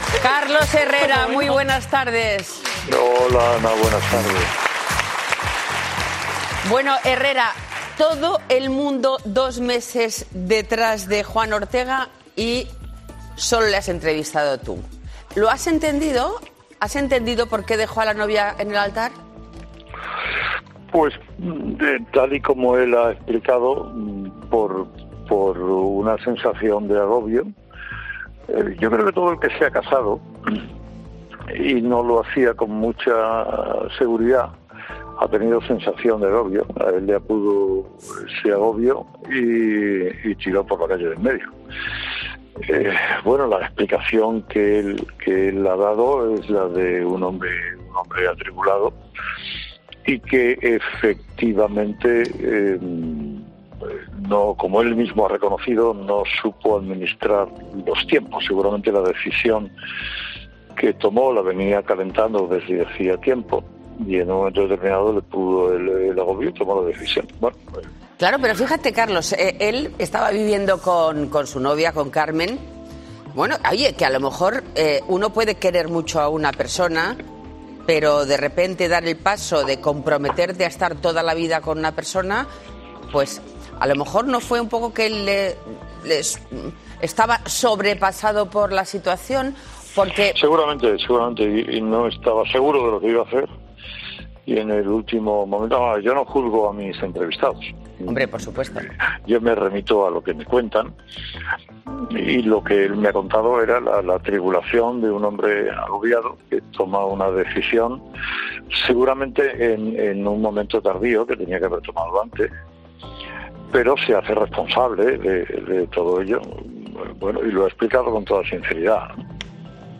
El director de Herrera en COPE ha pasado por el programa de Telecinco 'TardeAR' para dar su versión sobre la mediática entrevista al torero